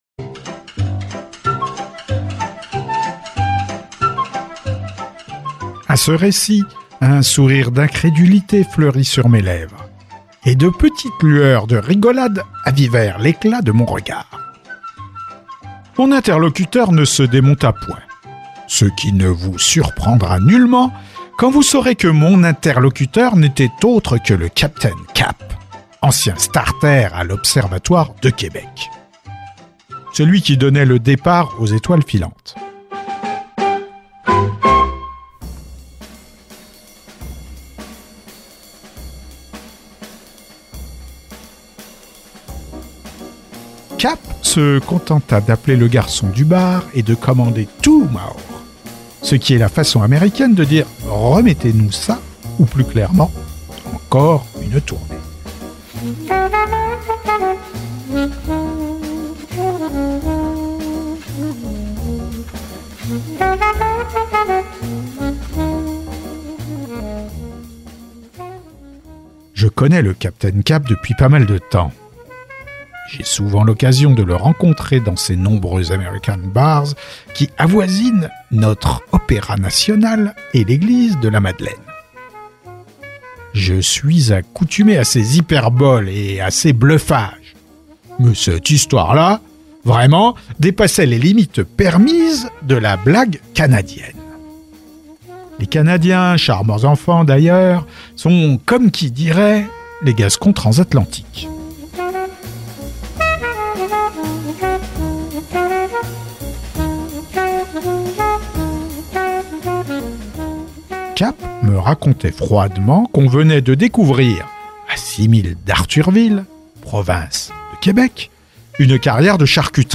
🎧 The Meat-Land, Fécondité artificielle & Une bonne œuvre – Alphonse Allais - Radiobook